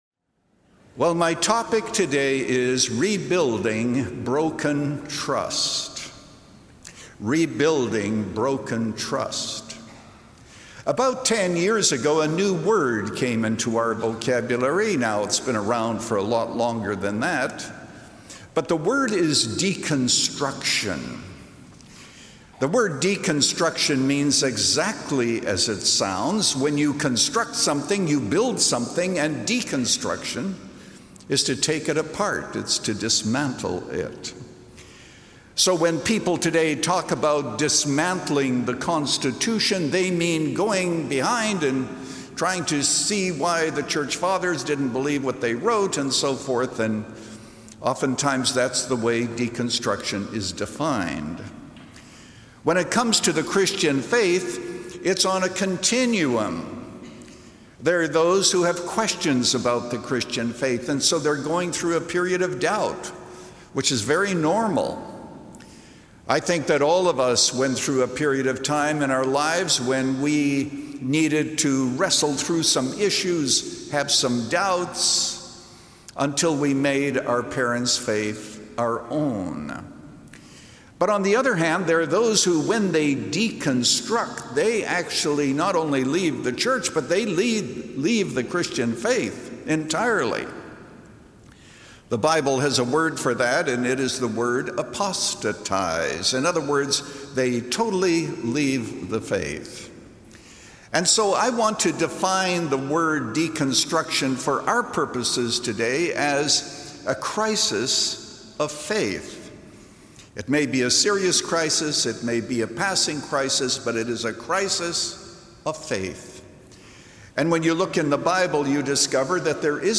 Selected highlights from this sermon